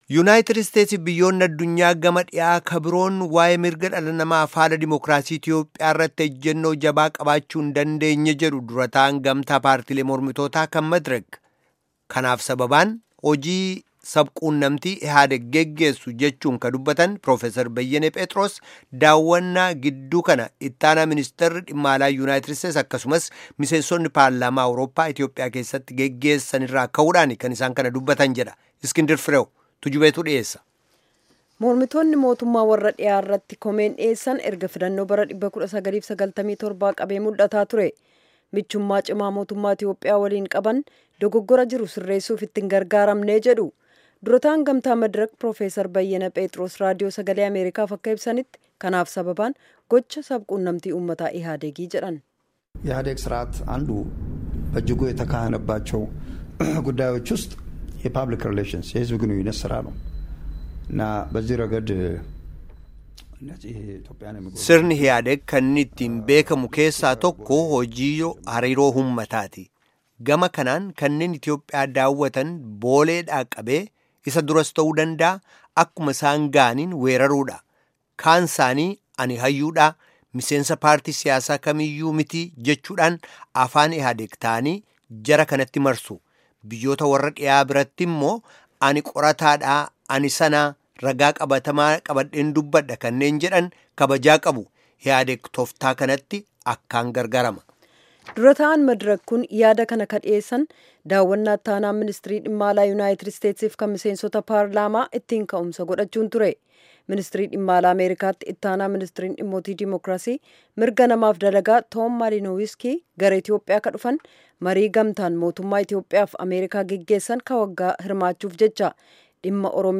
Gabaasa sagalee.